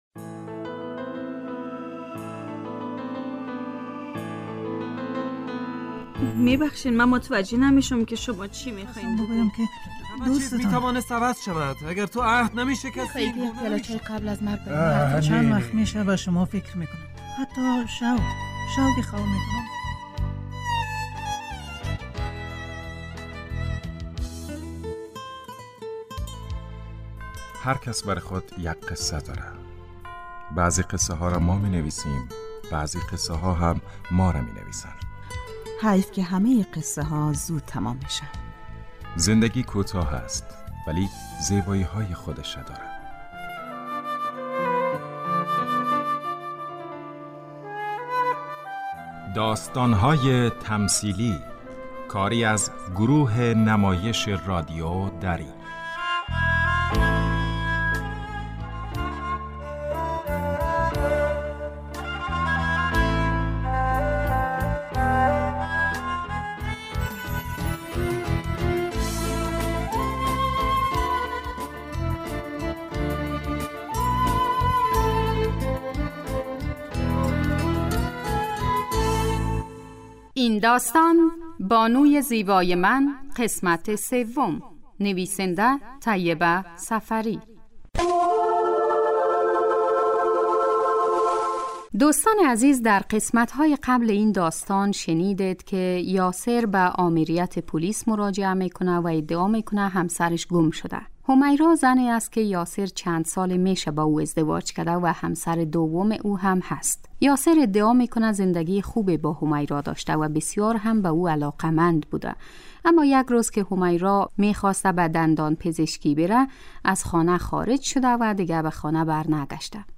داستان تمثیلی / بانوی زیبای من